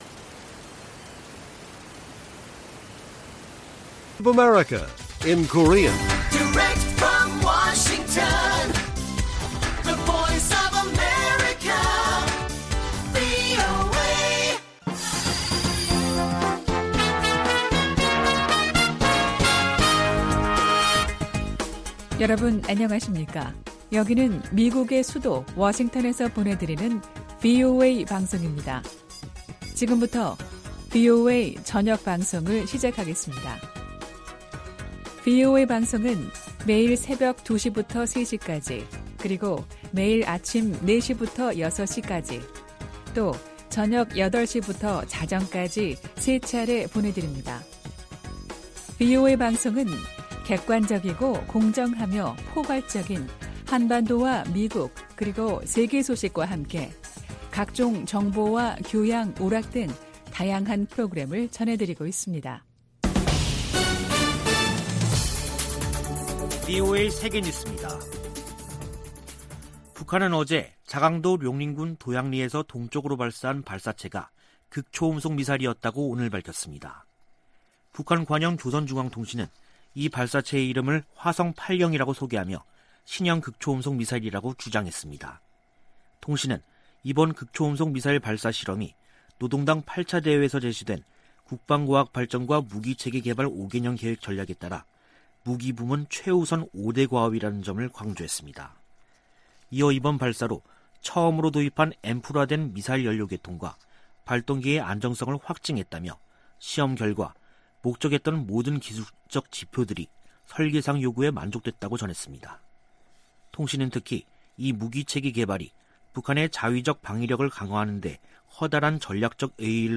VOA 한국어 간판 뉴스 프로그램 '뉴스 투데이', 2021년 9월 29일 1부 방송입니다. 미국 국무부 고위 관리가 북한의 최근 탄도미사일 발사들을 우려하며 규탄한다고 밝혔습니다. 북한이 유화적 담화를 내놓은 뒤 미사일을 발사한 것은 대미 협상에서 우위를 확보하기 위한 것으로 미 전직 관리들은 분석했습니다. 국제사회는 북한의 탄도미사일 발사를 규탄하고, 불법 행위를 멈출 것을 촉구했습니다.